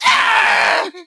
nita_hurt_03.ogg